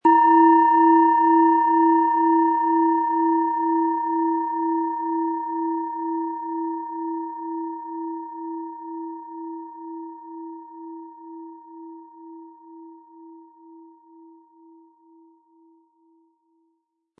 Tibetische Herz-Bauch-Kopf- und Schulter-Klangschale, Ø 12,2 cm, 180-260 Gramm, mit Klöppel
Im Sound-Player - Jetzt reinhören können Sie den Original-Ton genau dieser Schale anhören.
HerstellungIn Handarbeit getrieben
MaterialBronze